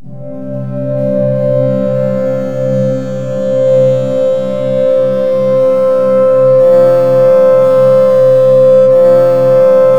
WIRE PAD1.wav